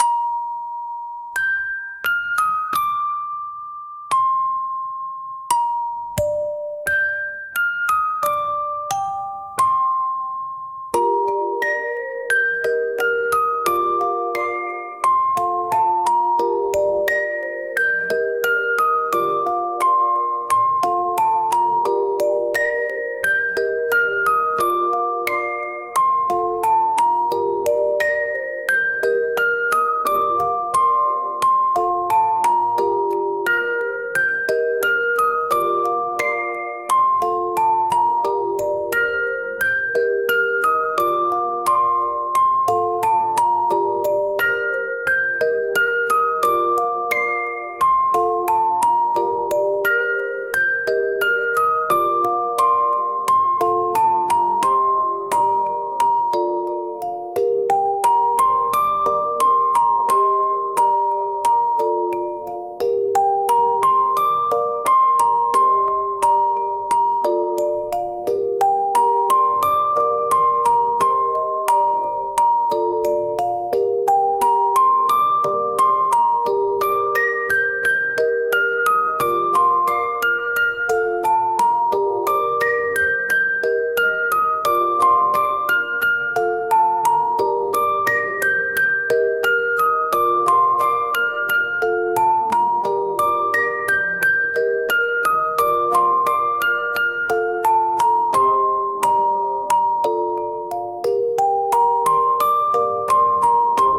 「オルゴール」カテゴリーの関連記事